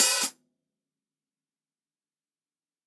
TUNA_OPEN HH_2.wav